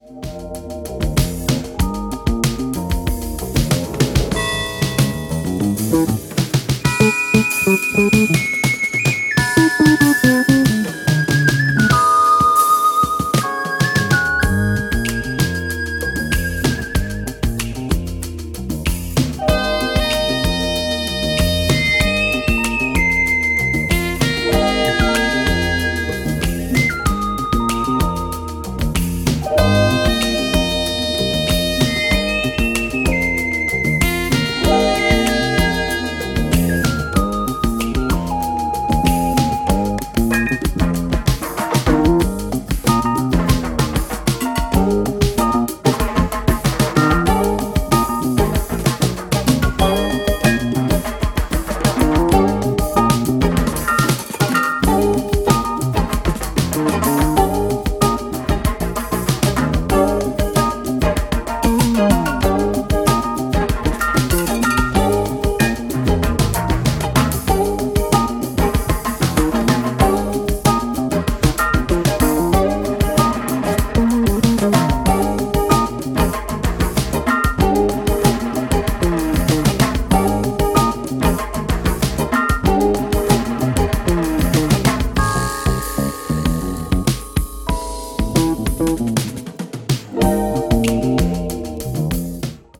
ジャンル(スタイル) JAZZ / BALEARIC / NU DISCO / EDITS